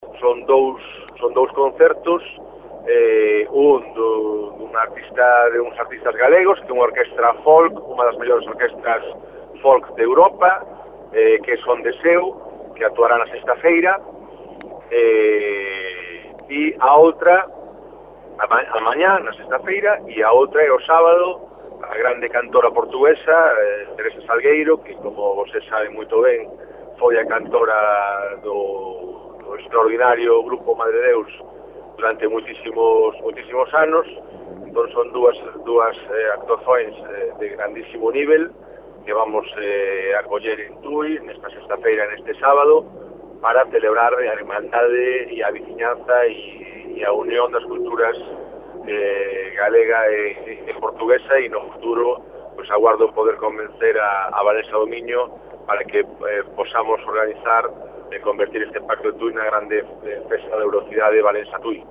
Como explicou à Rádio Caminha o alcaide de tui, Carlos Padín, este é o primeiro ano em que se comemora este episódio histórico que assinala um pacto politico de grande relevância histórica.